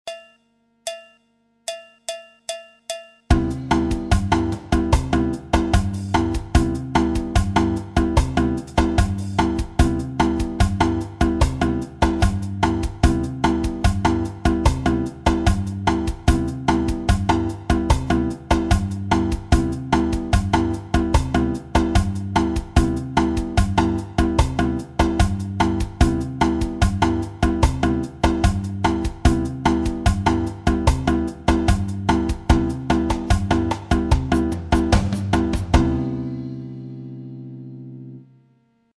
J'ai mis un pattern de batterie avec clave bossa, tambourim 1 bossa.
La guitare imite le surdo dans la bossa nova
la bossa nova imitation surdo